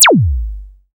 zapTTE47003zaps.wav